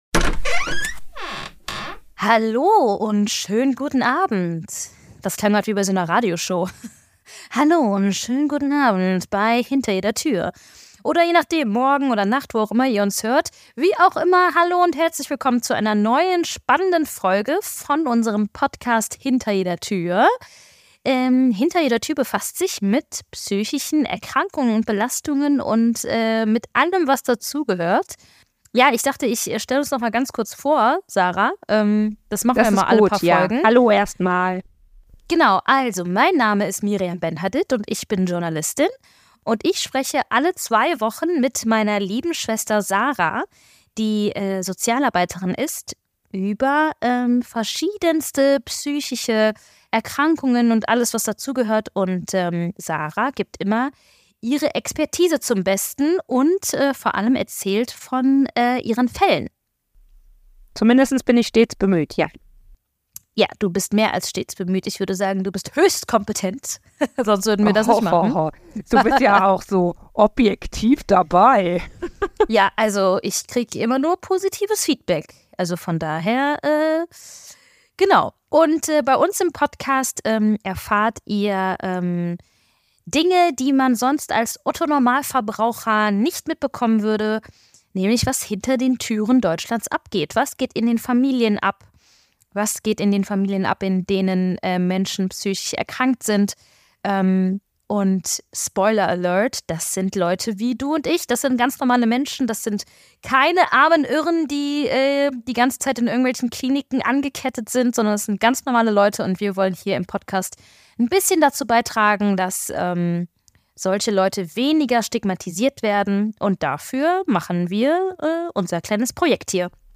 In der ersten Folge über das spannende psychische Phänomen sprechen die Schwestern über die Definition und Ursachen einer Psychose.
Wir sprechen offen und locker über diese Themen - gegen Stigmatisierung und für Offenheit und Toleranz.